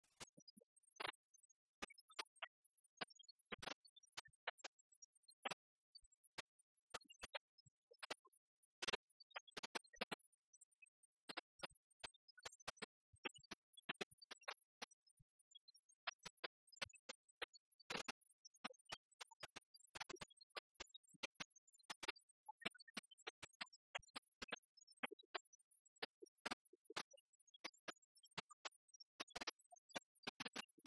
Mémoires et Patrimoines vivants - RaddO est une base de données d'archives iconographiques et sonores.
Genre laisse
Catégorie Pièce musicale inédite